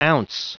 Prononciation du mot ounce en anglais (fichier audio)
Prononciation du mot : ounce